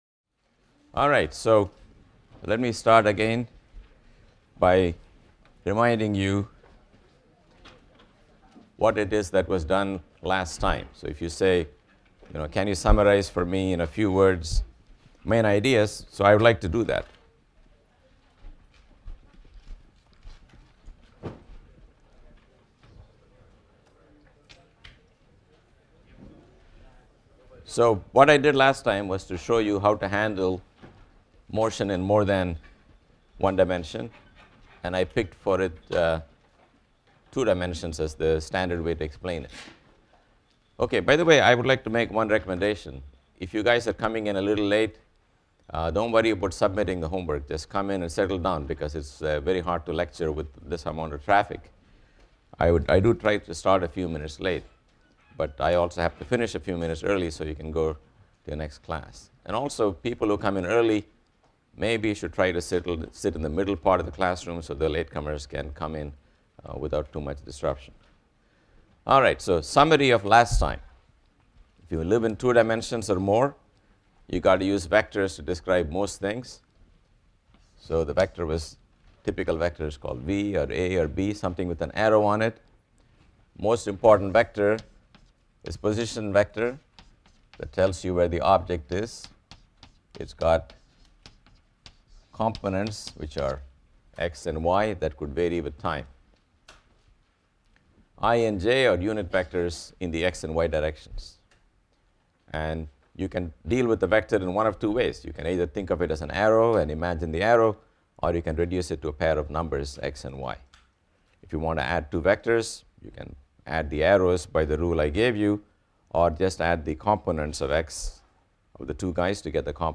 PHYS 200 - Lecture 3 - Newton’s Laws of Motion | Open Yale Courses